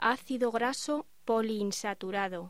Locución: Ácido graso poliinsaturado